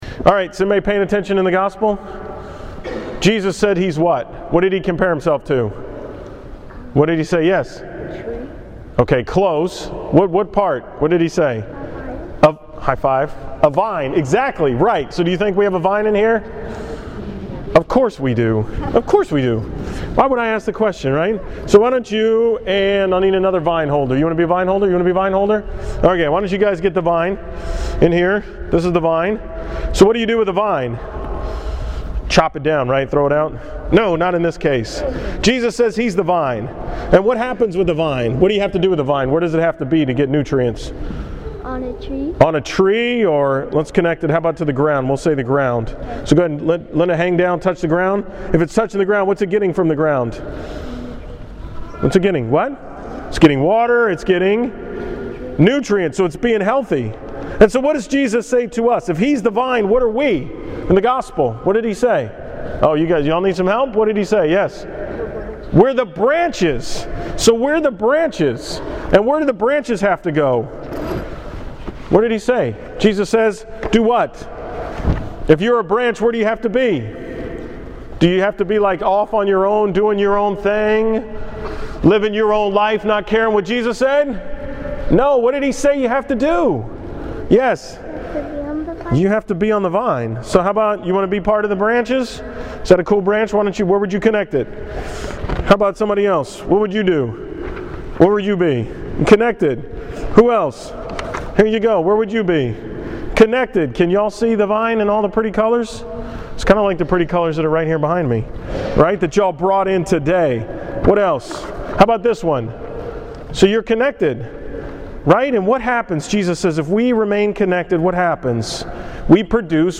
From the school Mass on Wednesday, May 1st
Category: 2013 Homilies, School Mass homilies